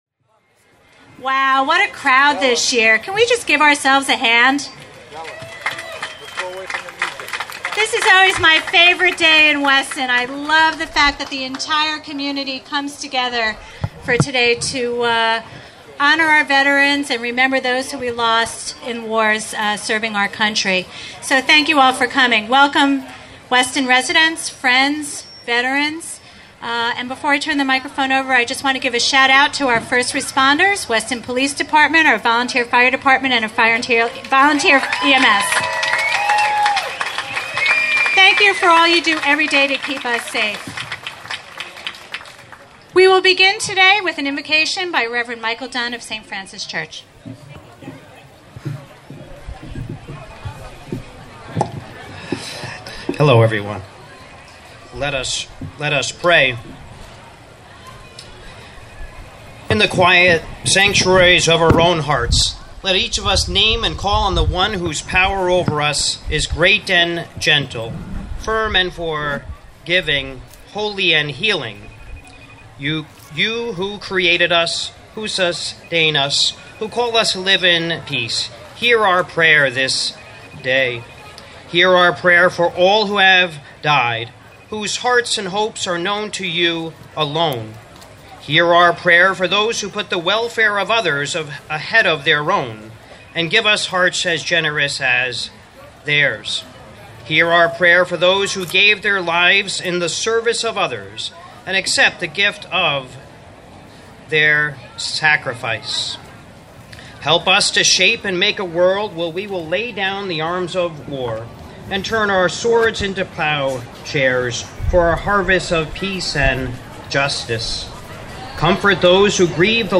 Very special music by brass ensemble
audio recording of 2015 Memorial Day event from Town Green.
MemorialDayCeremony5-25-15.mp3